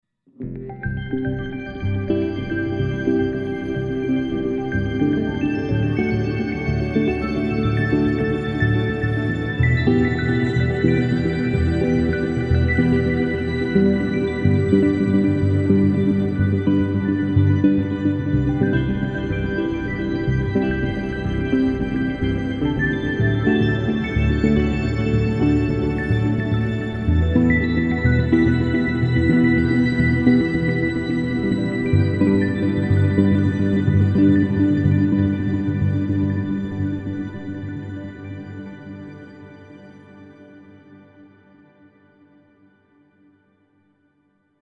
Create great sounding ambient music with just your guitar and Headrush with this pack from NVA.
A. SWELL - Toggle the swell effect
GRAIN - Toggle the grain delay
RAW AUDIO CLIPS ONLY, NO POST-PROCESSING EFFECTS